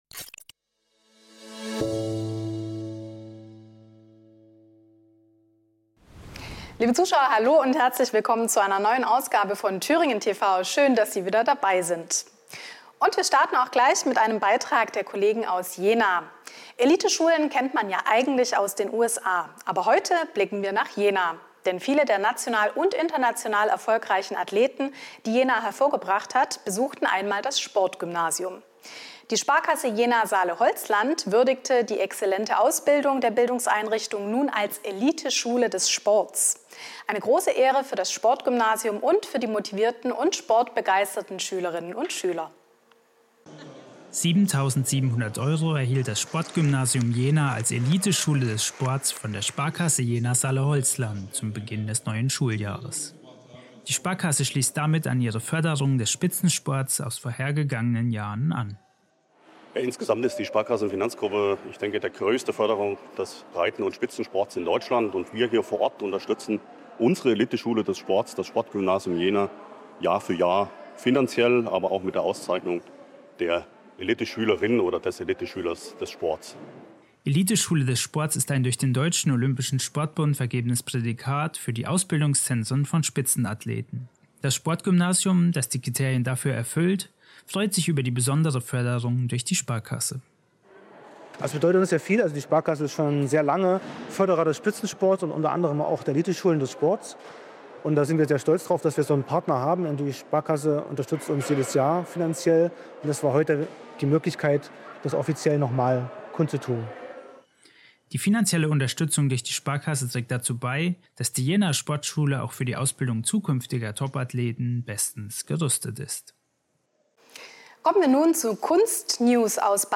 Die Th�ringer Lokalsender pr�sentieren die Sommerinterviews mit f�hrenden Landespolitikern. Heute ver�ffentlichen wir das mit Katja Wolf (BSW), der Stellvertreterin des Th�ringer Ministerpr�sidenten, gef�hrte Gespr�ch.